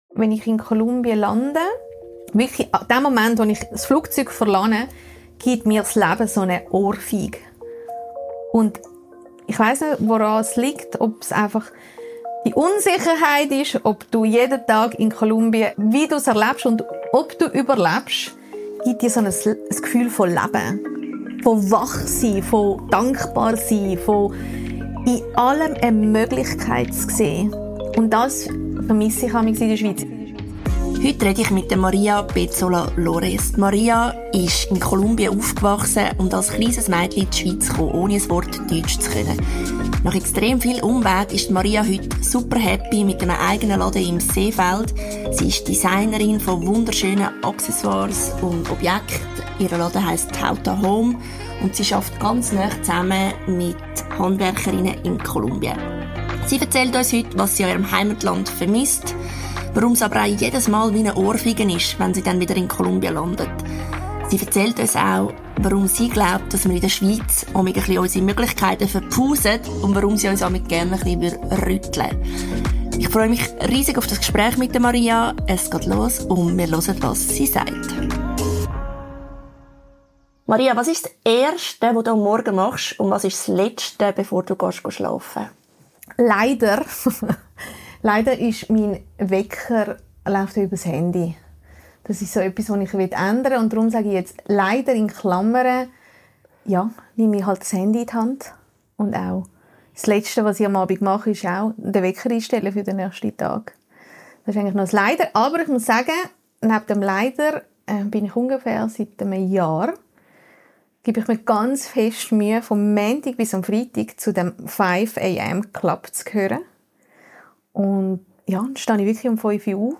Ein Gespräch über Identität, Herkunft, Unternehmertum und die Frage, was es bedeutet, im Jetzt zu leben – zwischen Sicherheit und Mut.